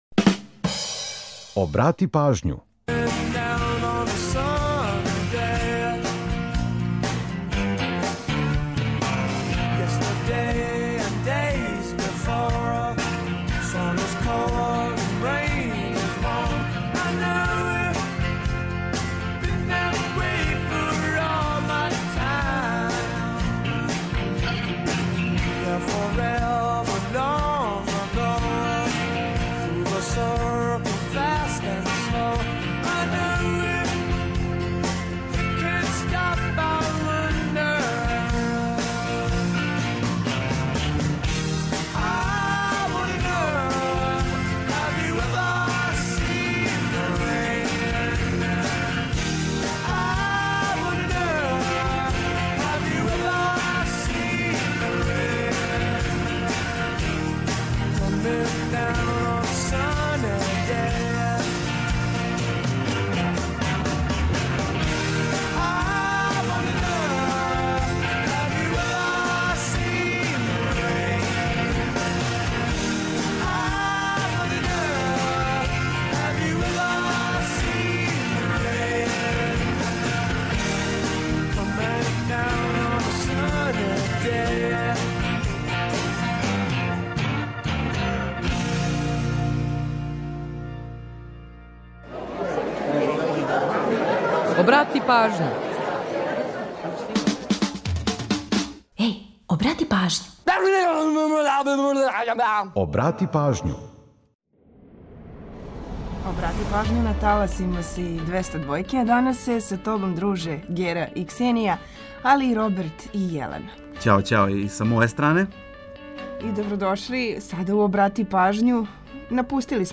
Ту је и пола сата резервисано само са музику из Србије и региона, а упућујемо вас и на нумере које су актуелне.